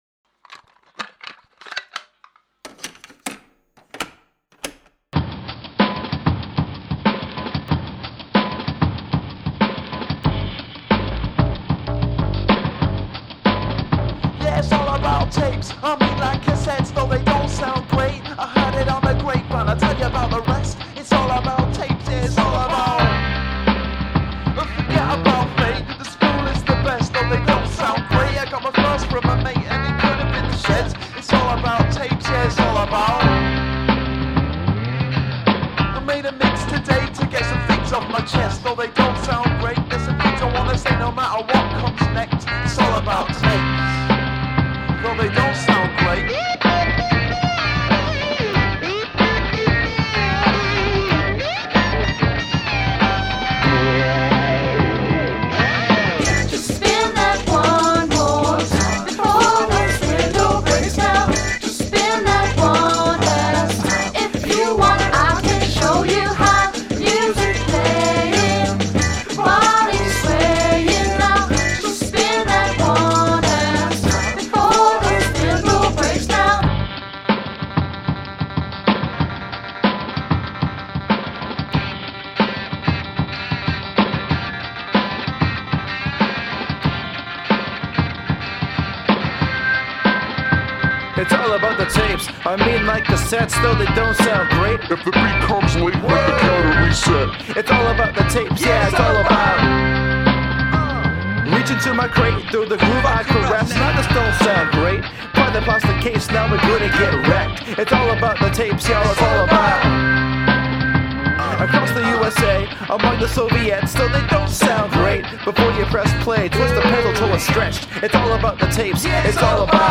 Villanelle
Funky. Great vocals. Cool guitar.